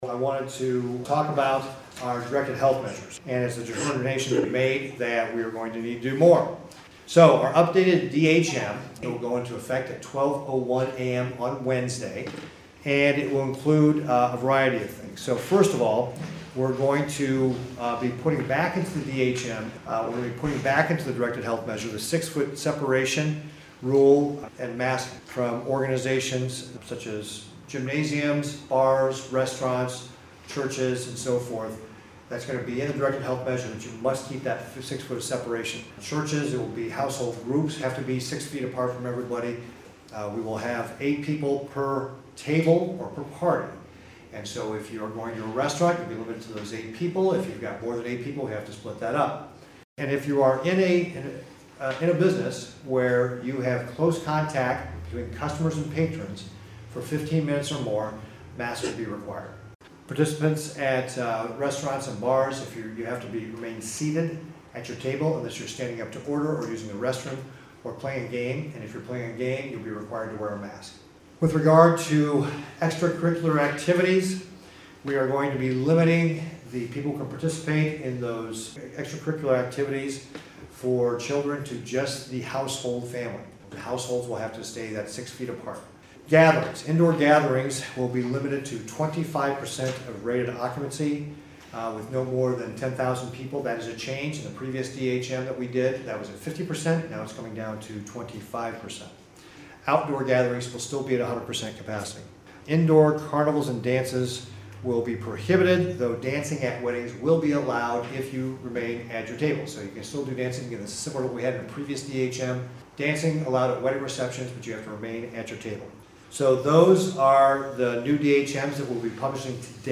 NEBRASKA – During Nebraska Governor Pete Ricketts morning press conference, new Directed Health Measures (DHM’s) were announced that will become effective Wednesday, November 11 at midnight and be effective until at least November 30, 2020.
Listen to Gov. Rickett’s explain the new DHM measures here